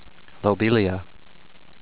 low-BIEL-ee-uh